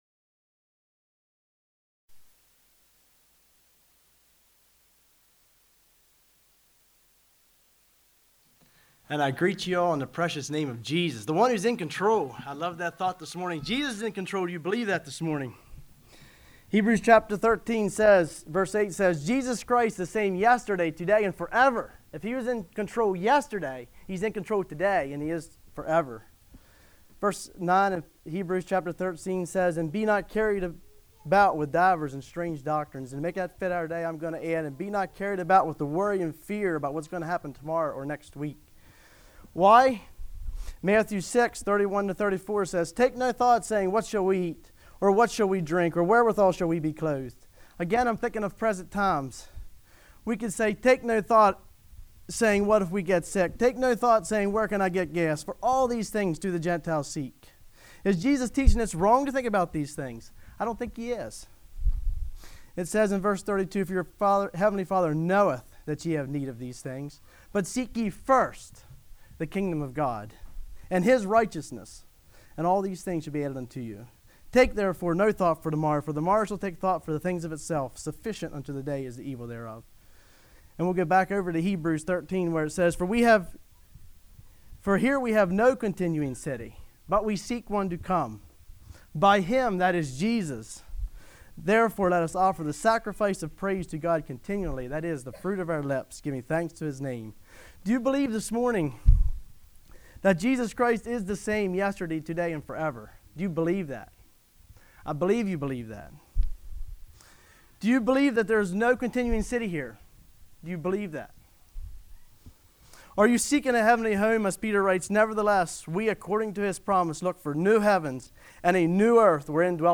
2021 Sermon ID